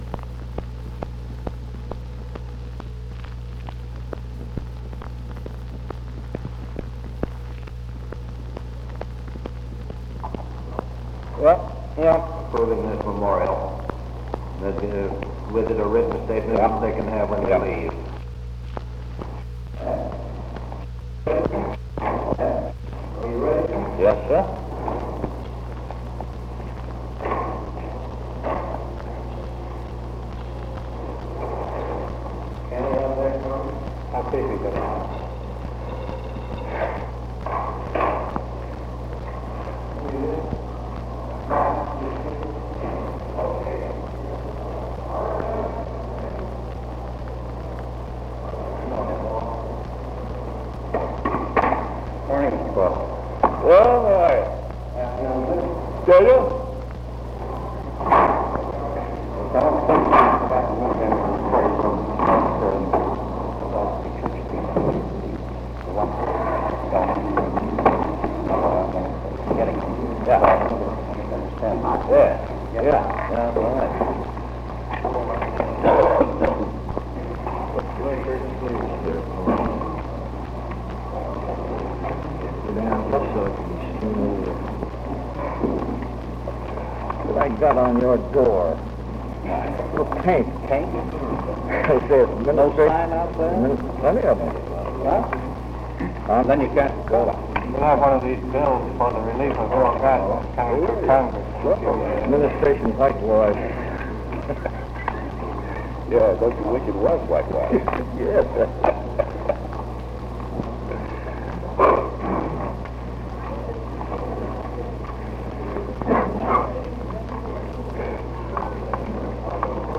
Press Conference 691